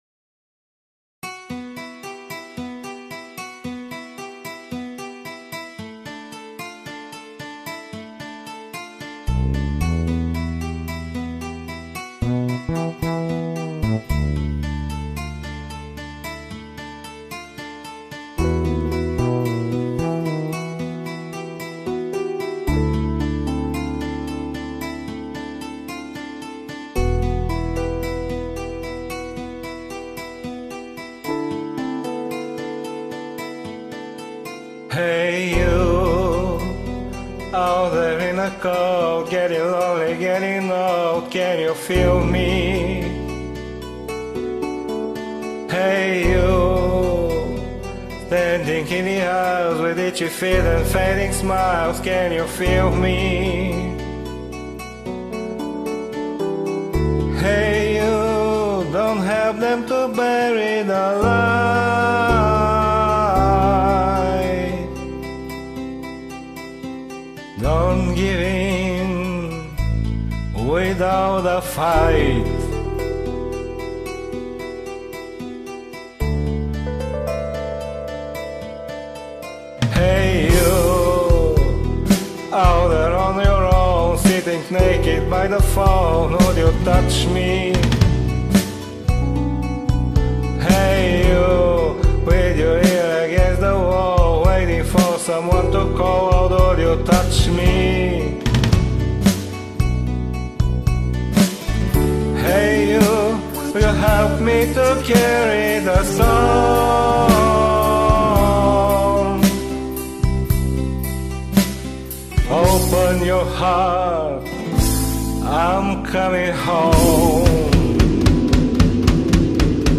Моя запись сделана онлайн с телефона.